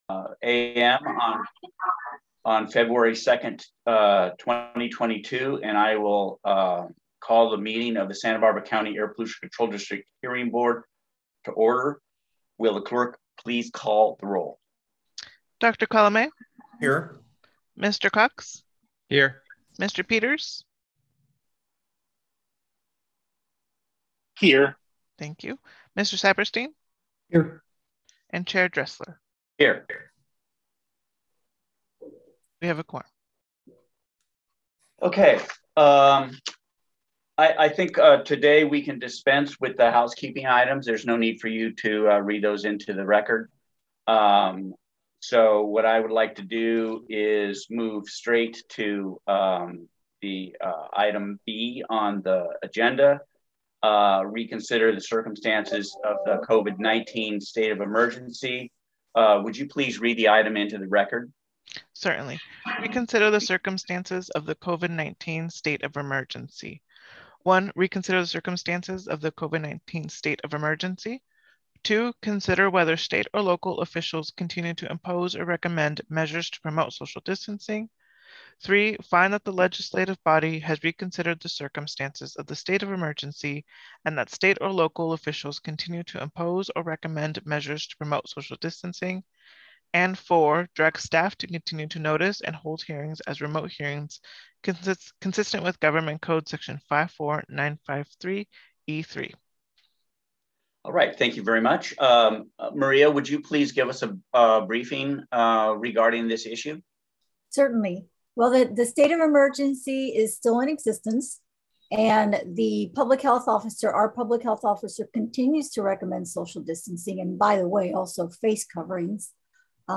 *REMOTE VIRTUAL PARTICIPATION ONLY